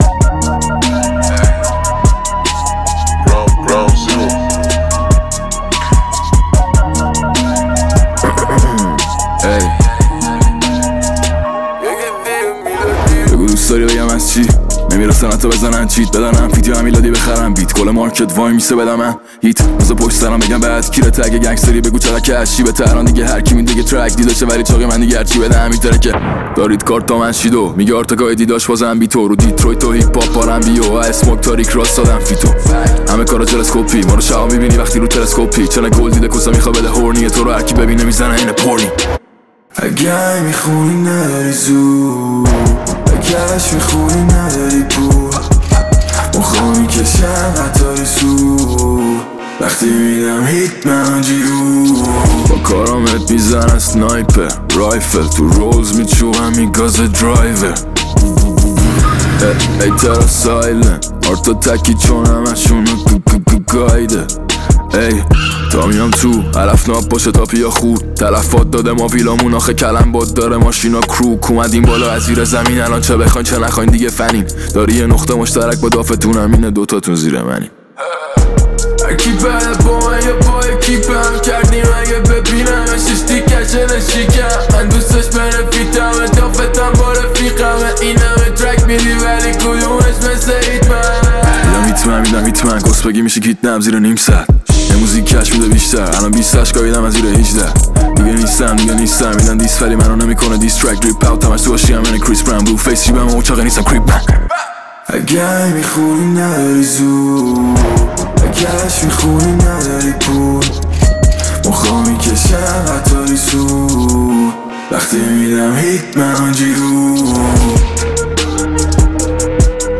آهنگ جدید و بسیار متفاوت و دلنشین